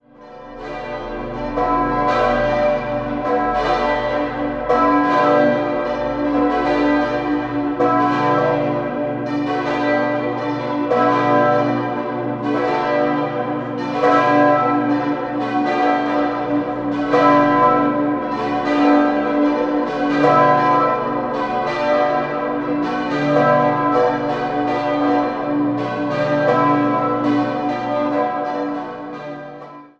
5-stimmiges Geläut: h°-dis'-fis'-gis'-h' Alle Glocken wurden 1958 von der Gießerei Grassmayr in Innsbruck gegossen und ersetzen ein Gussstahlgeläut der Firma Böhler, welches zur Erinnerung vor der Kirche aufgestellt ist.